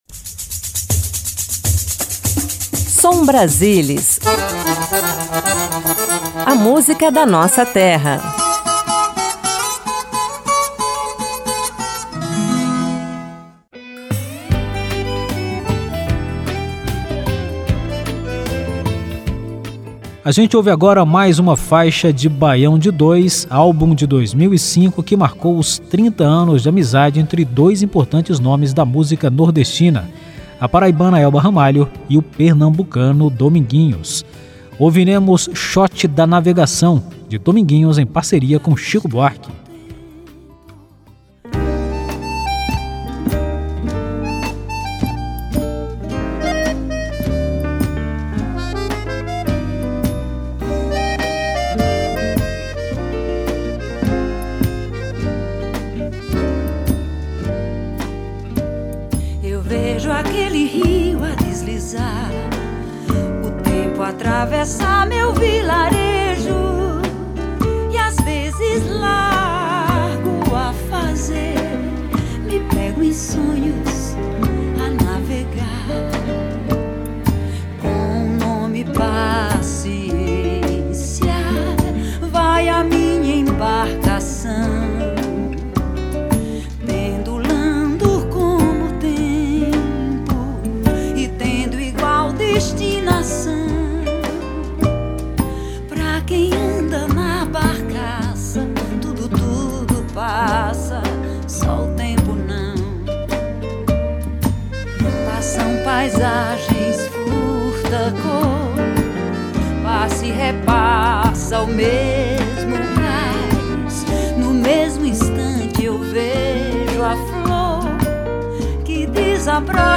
MPB
Ritmos nordestinos
Forró
Xote
Baião
Ao vivo